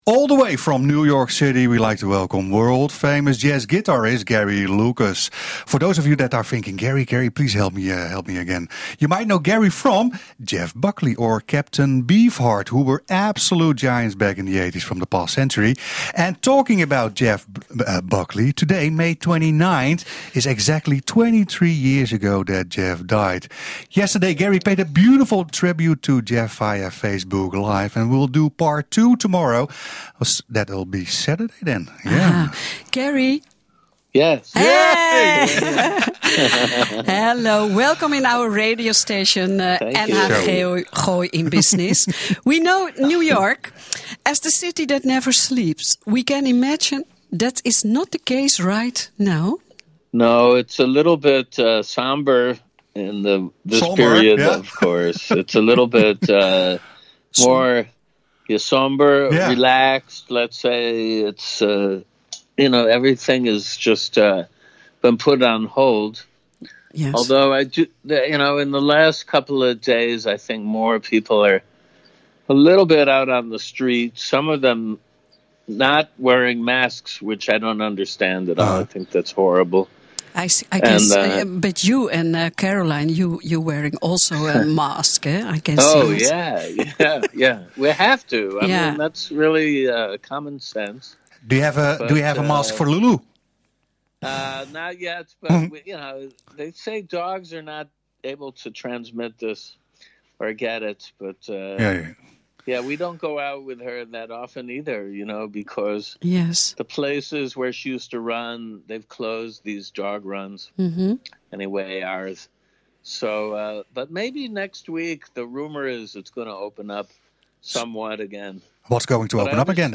omdat we nu met New York bellen … schakelen we moeiteloos over op het engels … want …. all the way from New York City we like to welcome world famous jazz guitarist Gary Lucas ….. for those of you that are thinking …
gooische-business-world-famous-jazz-guitarist-gary-lucas-pays-tribute-friend-jeff-buckley.mp3